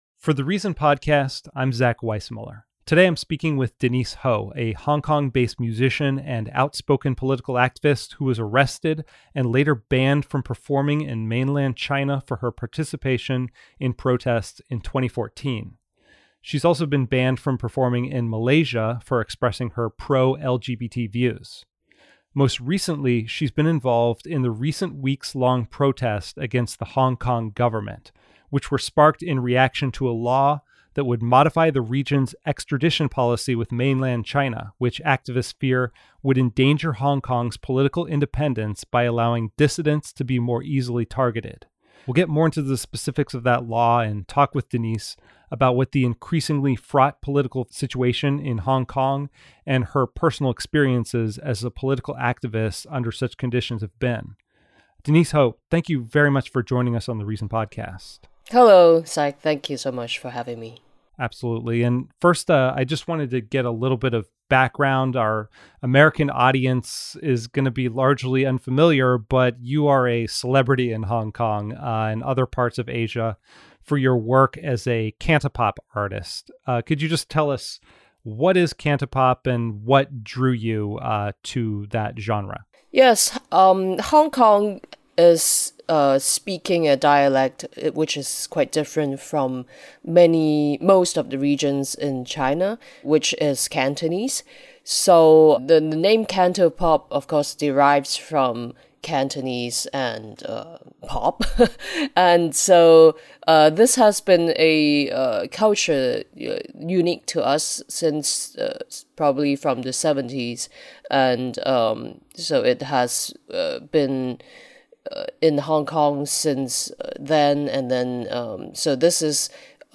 Activist and celebrity musician Denise Ho discusses the Hong Kong protests, her 2014 arrest, and the future of Hong Kong's autonomy from China.